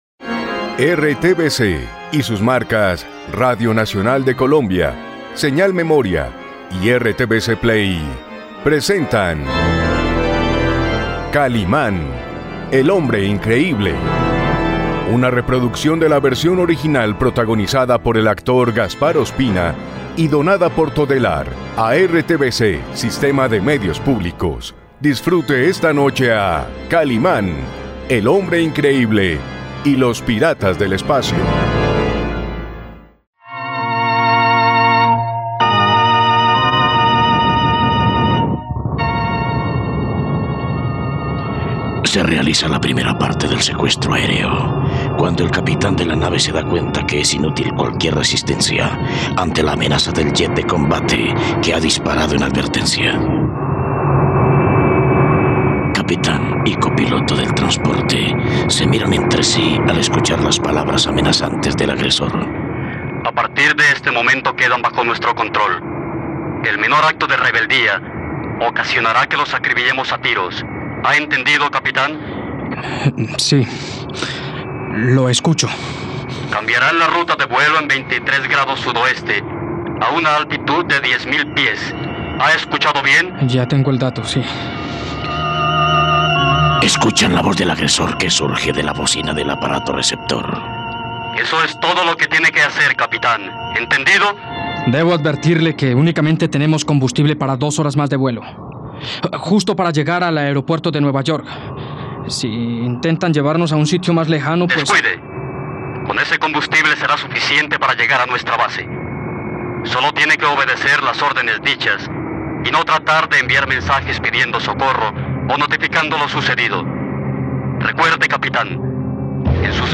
Kásmar detalla los planes de la organización a Omar y se dirige a la cabina del avión con un arma en su mano. Asset ID 0 Arriba 0% Down 0% Producción Kalimán, el hombre increíble - Los Piratas del Espacio Tags criminales vuelo avión peligro radionovela Duración 20Minutos Archivo Kaliman piratas del espacio Cap 63 Con promos.mp3 (18.47 MB) Número de capítulo 63